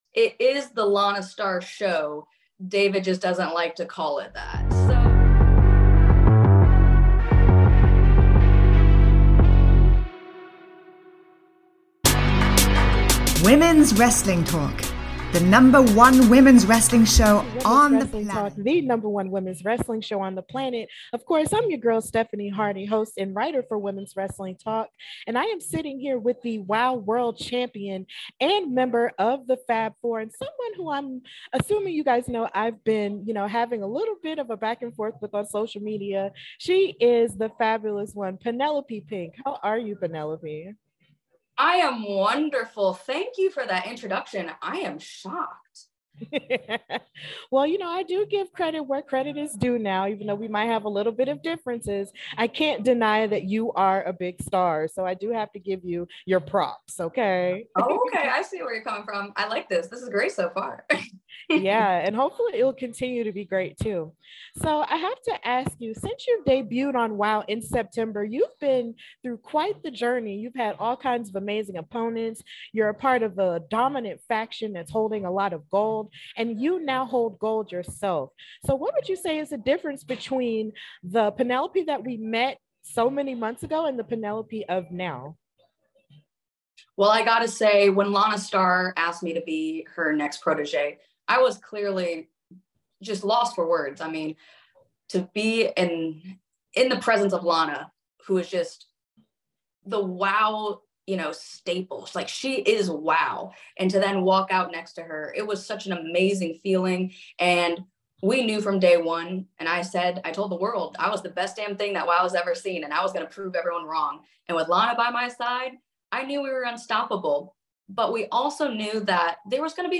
What happens when two fierce women with a social media rivalry have an interview?